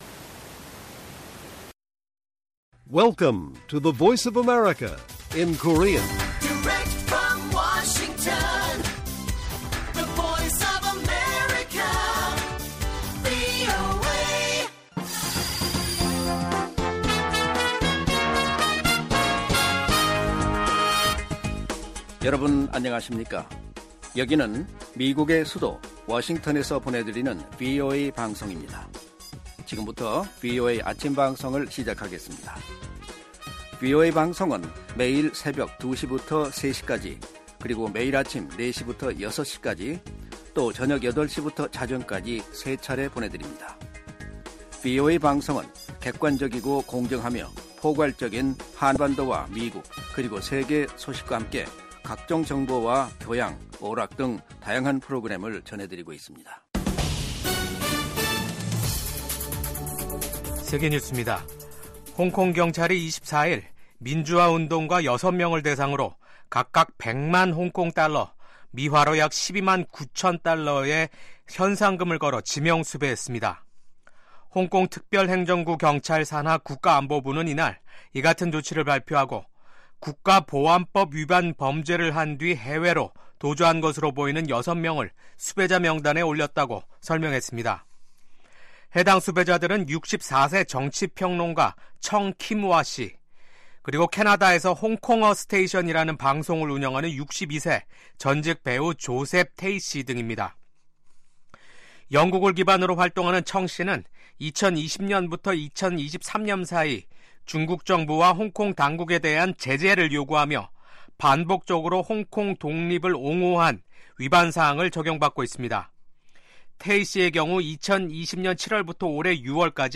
세계 뉴스와 함께 미국의 모든 것을 소개하는 '생방송 여기는 워싱턴입니다', 2024년 12월 25일 아침 방송입니다. 볼로디미르 젤렌스키 우크라이나 대통령이 슬로바키아가 러시아 가스를 계속 수입해 블라디미르 푸틴 러시아 대통령을 도우려 한다고 비난했습니다. 에마뉘엘 마크롱 프랑스 대통령이 올해 들어 네 번째 내각을 구성했습니다. 필리핀이 미군의 ‘타이폰(Typhon)’ 중거리 미사일 체계 도입을 결정했습니다.